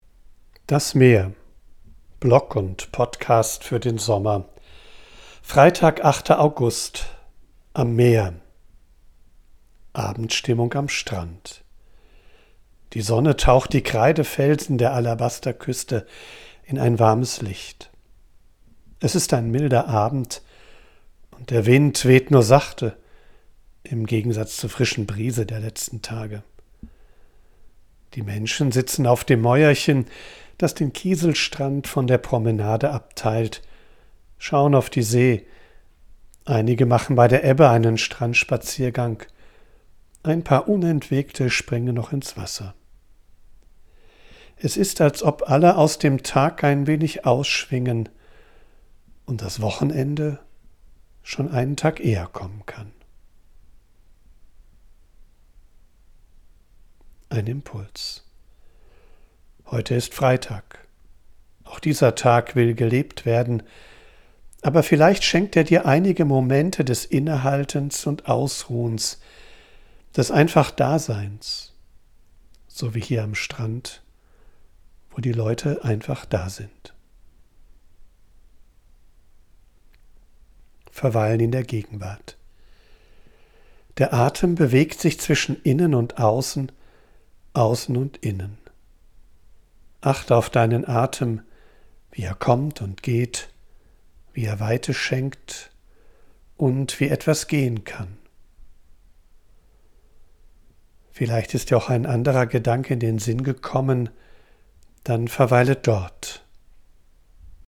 Ich bin am Meer und sammle Eindrücke und Ideen.
von unterwegs aufnehme, ist die Audioqualität begrenzt.
mischt sie mitunter eine echte Möwe und Meeresrauschen in die